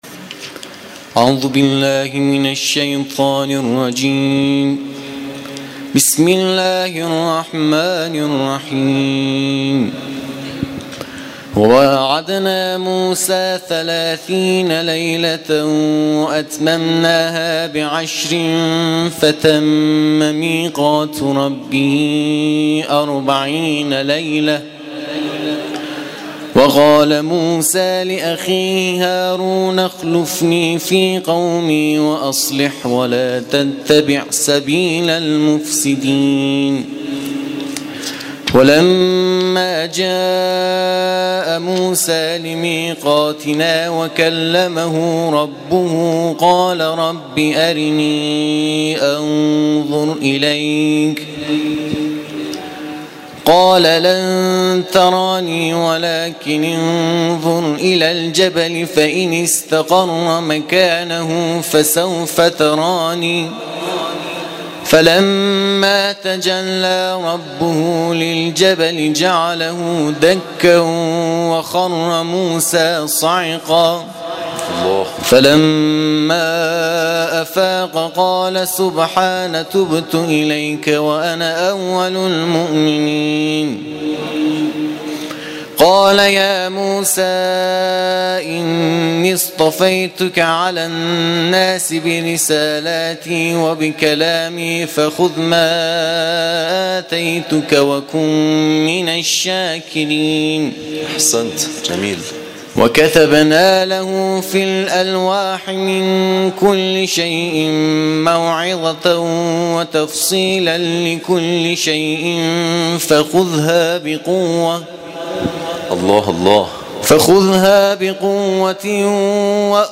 قرائت ترتیل‌